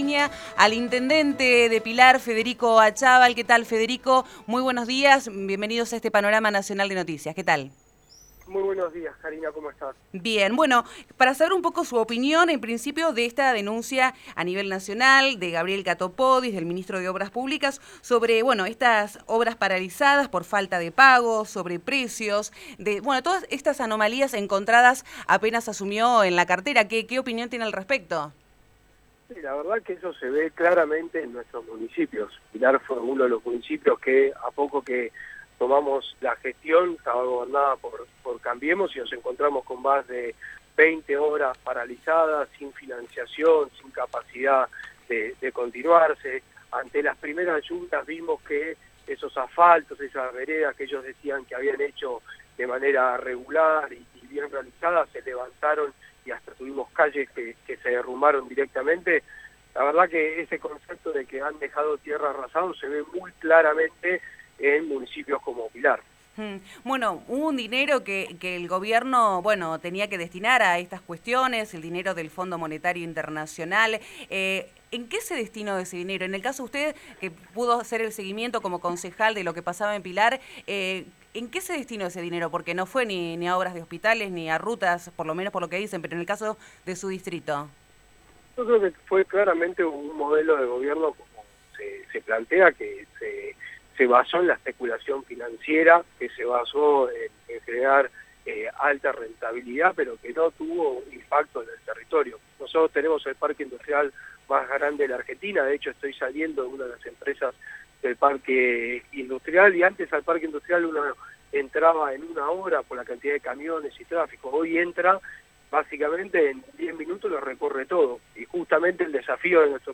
Entrevista a Federico Achával POLÍTICA
El intendente de Pilar, Federico Achával, habló por Radio Nacional sobre la situación en la que encontraron el municipio.
Federico-Achával-Intendente-Pilar.wav